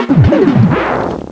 pokeemerald / sound / direct_sound_samples / cries / darmanitan.aif